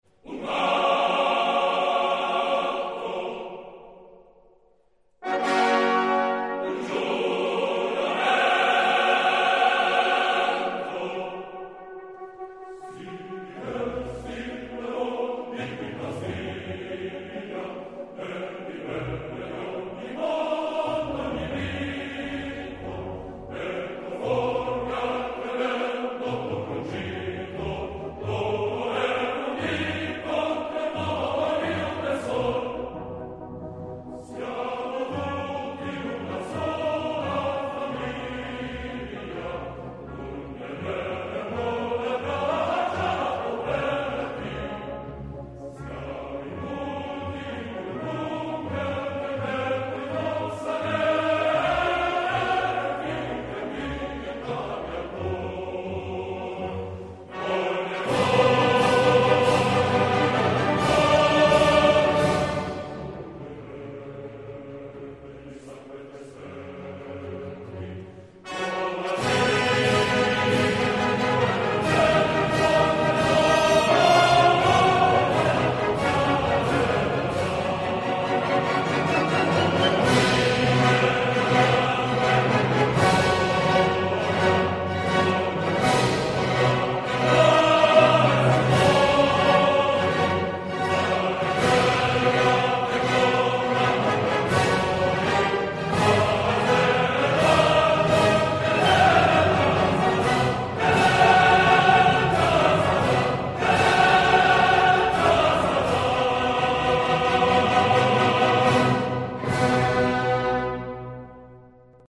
coro